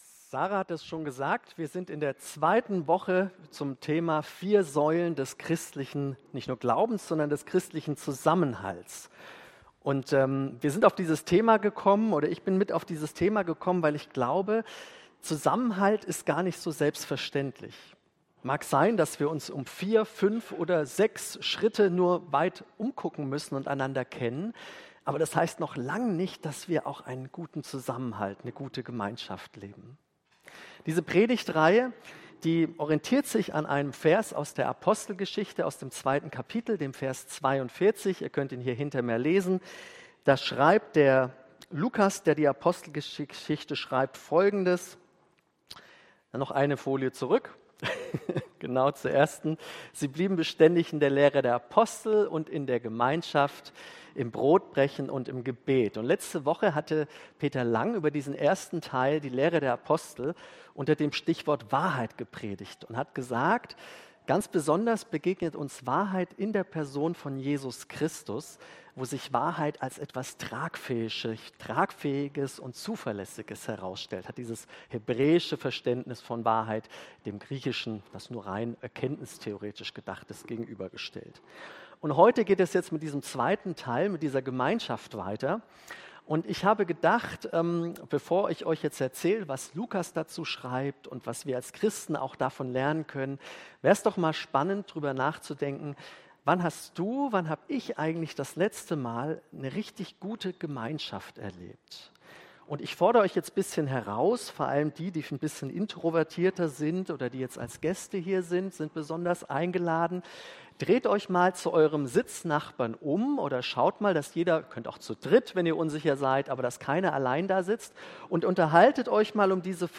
Predigt am Sonntag